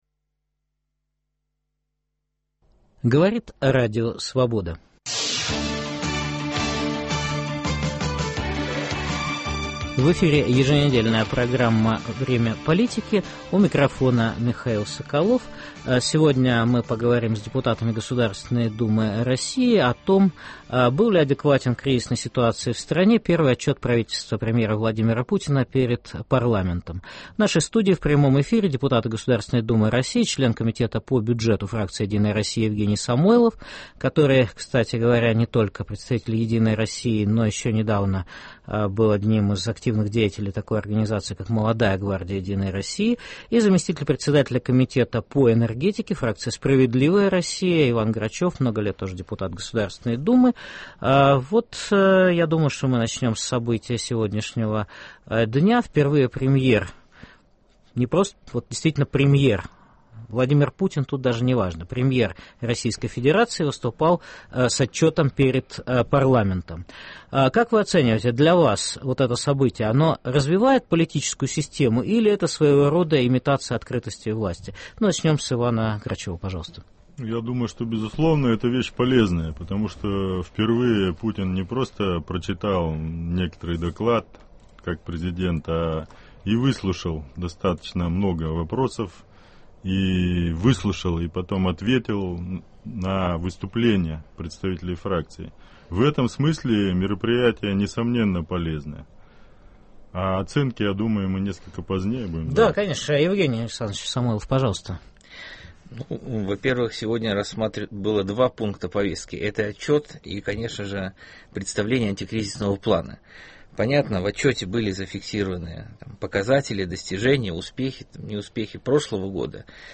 Был ли адекватен кризисной ситуации в стране первый отчет правительства премьера Владимира Путина перед парламентом? В прямом эфире дискутируют депутаты Государственной Думы России: член комитета по бюджету (фракция «Единая Россия») Евгений Самойлов и зампредседателя комитета по энергетике (фракция «Справедливая Россия») Иван Грачев.